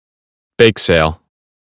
1. bake sale (n) /beɪk seɪl/ việc bán bánh nướng để gây quỹ từ thiện